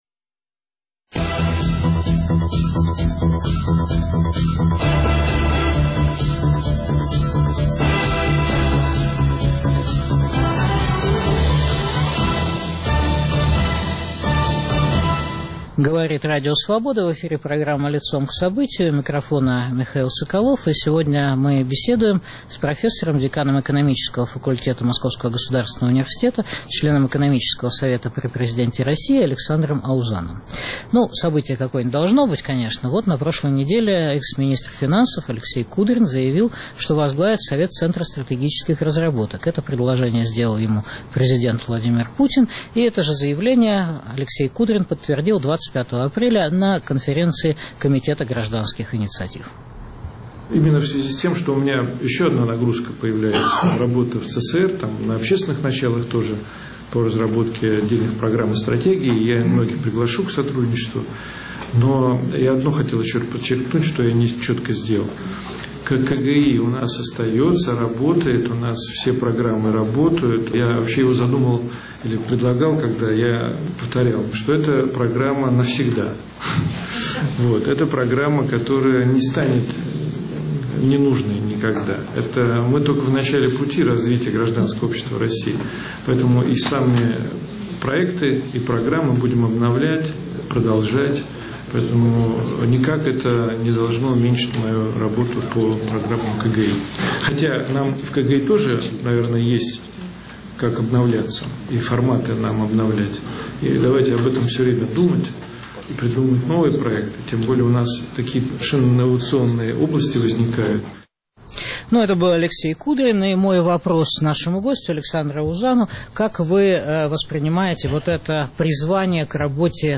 В эфире декан экономического факультета МГУ профессор Александр Аузан.